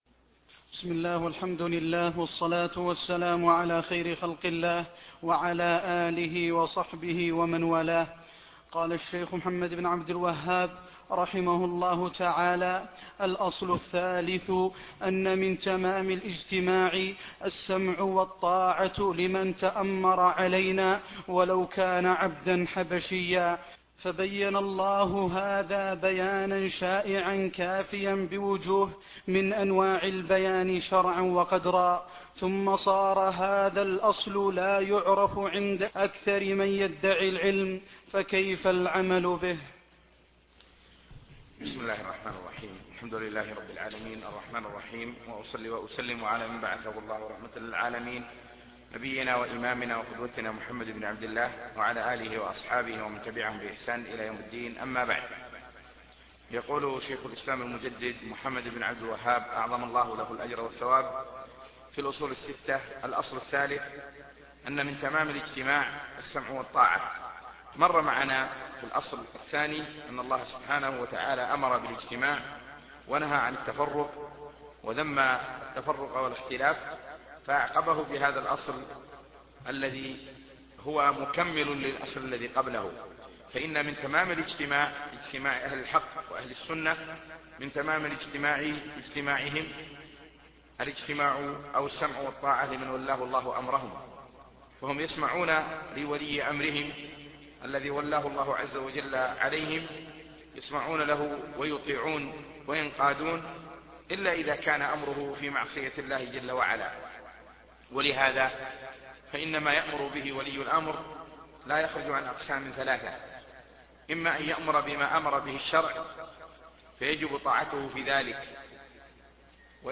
الدرس 2 الجمعة 7/4/1430هـ بعد المغرب